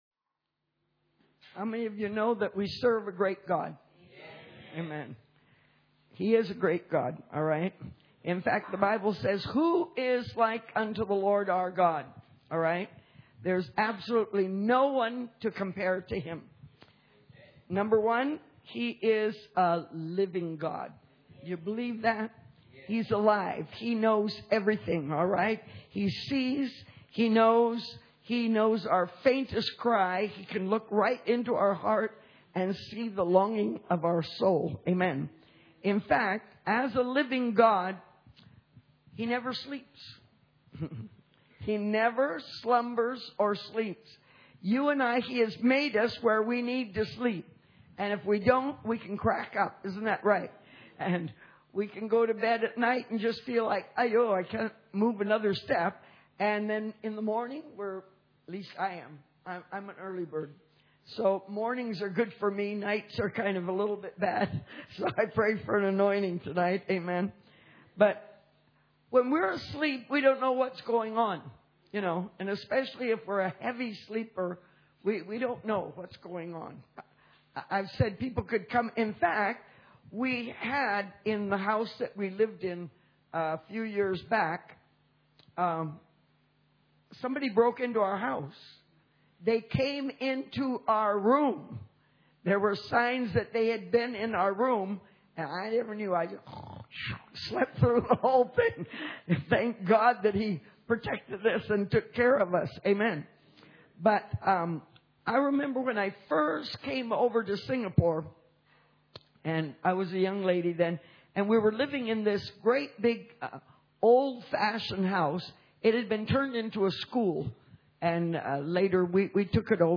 Series: Church Camp 2012